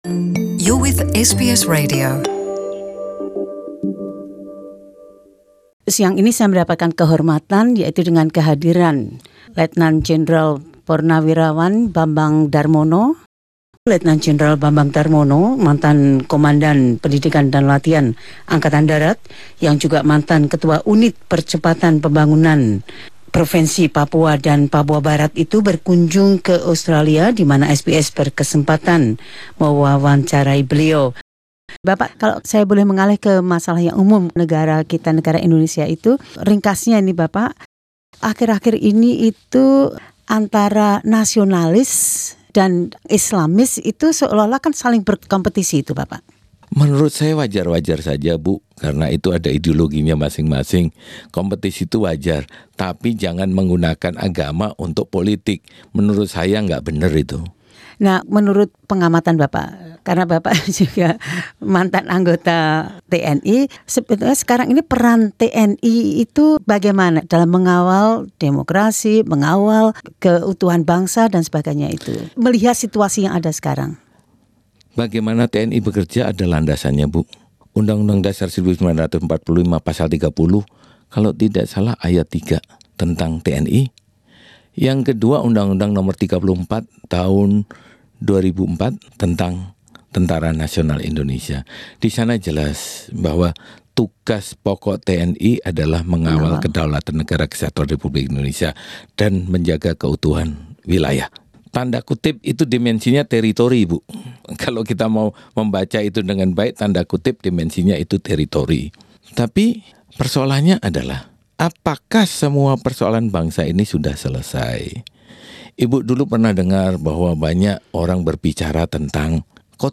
Letjen (Purn) Bambang Darmono berbicara tentang pengertiannya akan peran militer Indonesia dalam kompleksitas negara Indonesia masa kini.